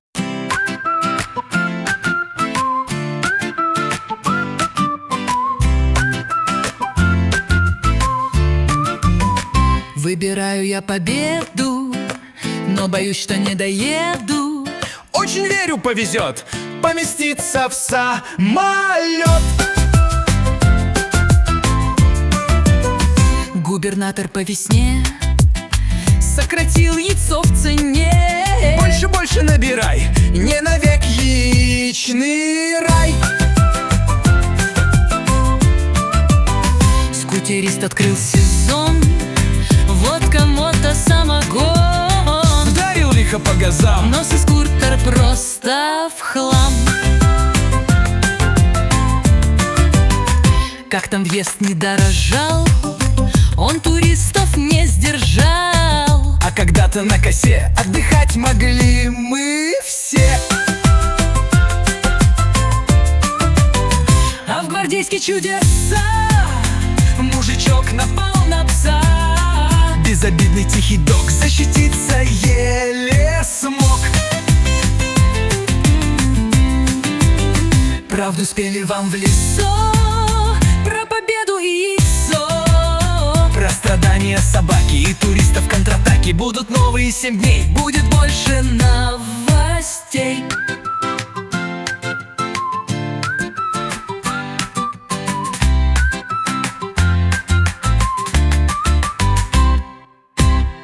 Комические куплеты о главных и важных событиях